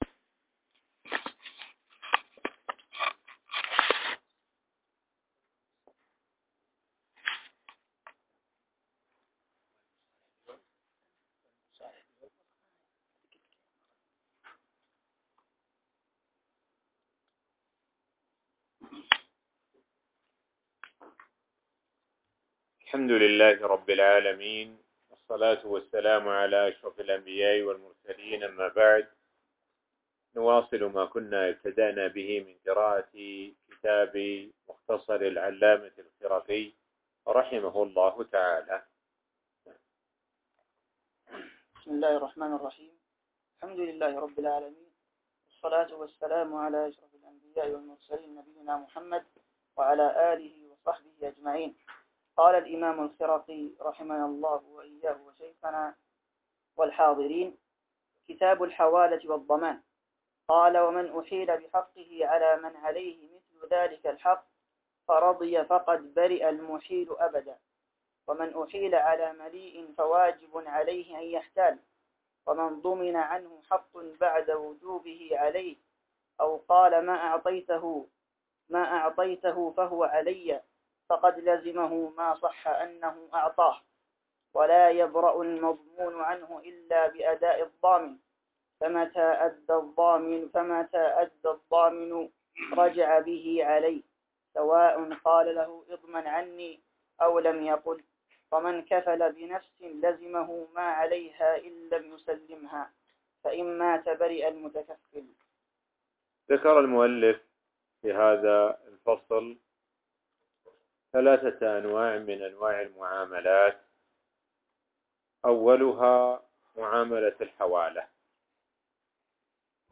الموقع الرسمي لفضيلة الشيخ الدكتور سعد بن ناصر الشثرى | الدرس--28 الحوالة والضمان والكفالة والشراكة